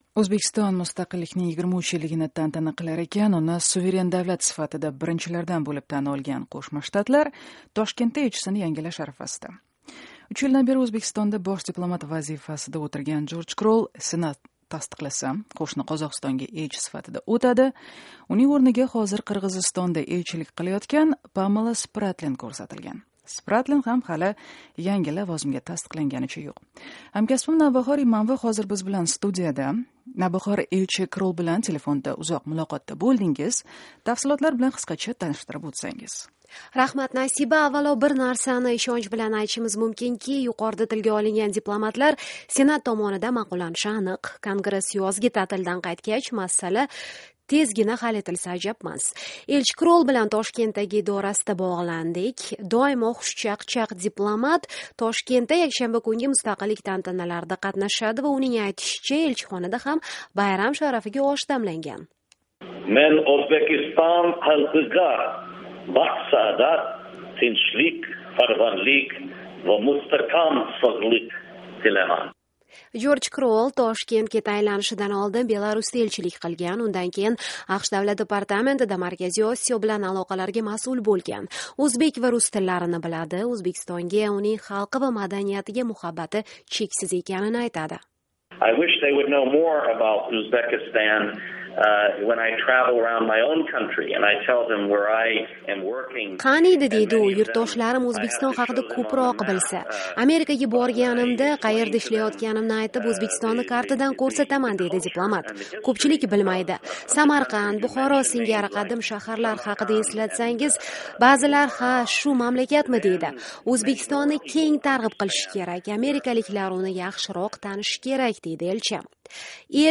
Elchi Krol bilan Toshkentdagi idorasida bog’landik.